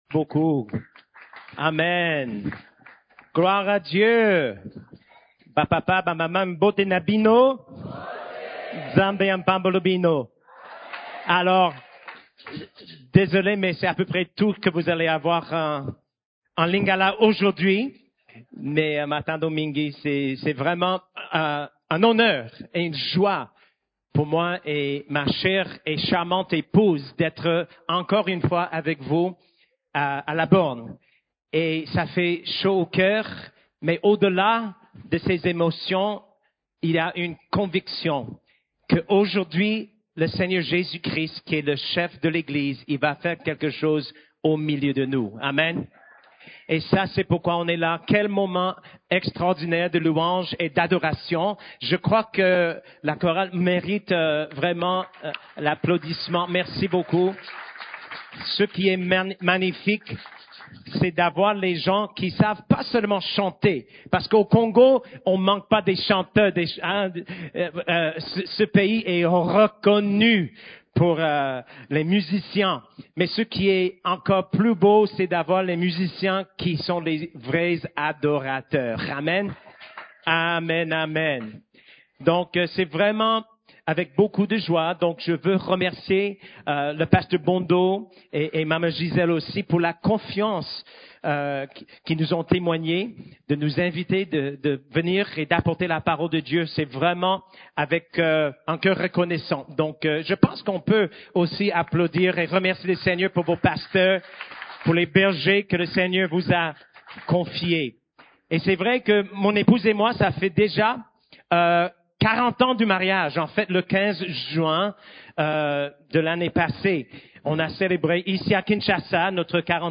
CEF la Borne, Culte du Dimanche, Victime ou Vainqueur : Comment marcher dans la révélation de notre union avec Christ ?